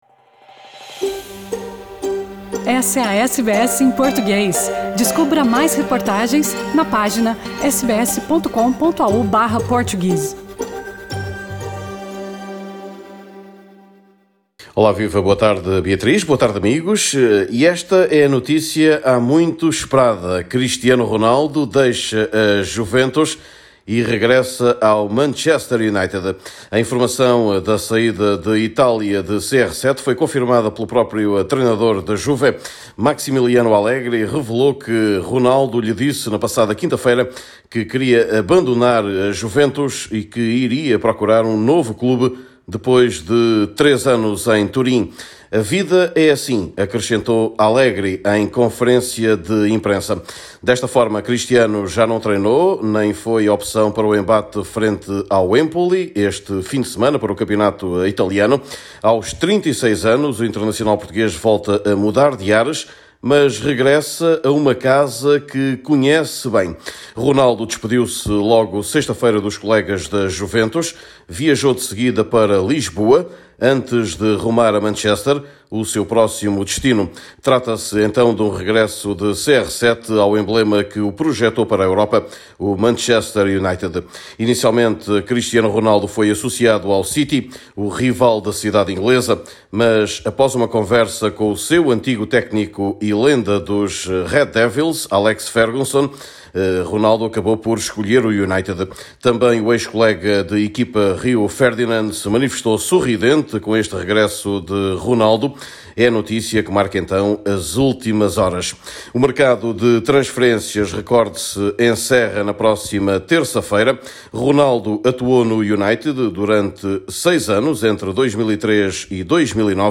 Neste boletim semanal